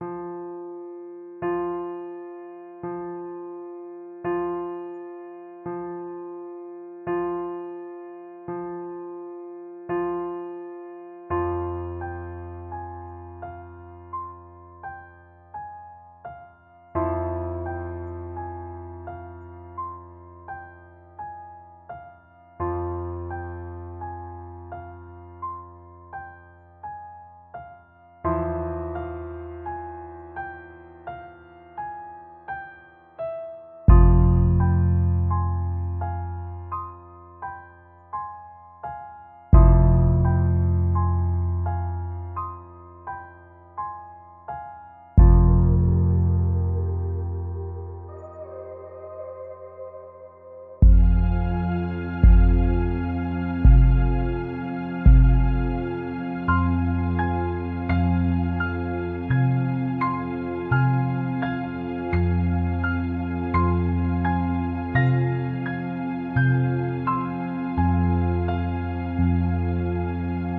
标签： 音乐 怪异 焦虑 怪异 环境 背景 理线SED 悬疑 诡异 黑暗 无人驾驶飞机 令人毛骨悚然 邪恶 恐怖 未知的心情 死亡的气息 恐怖
声道立体声